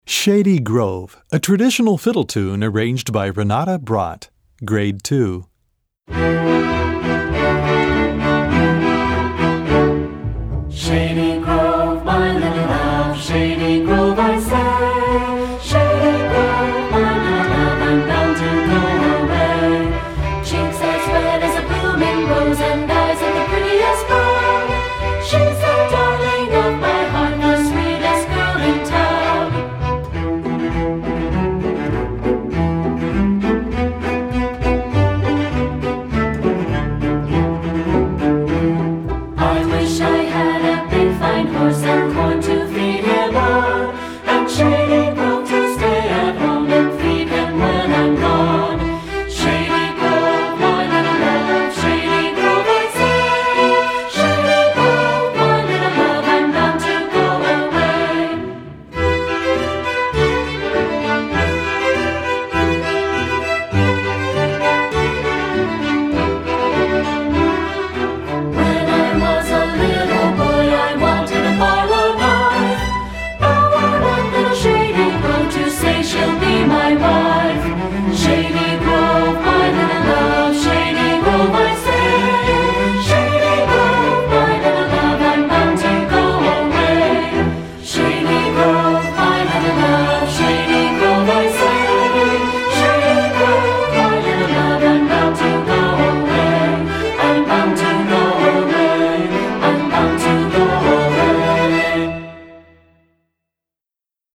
Gattung: Streichorchester
Besetzung: Streichorchester
This American fiddling song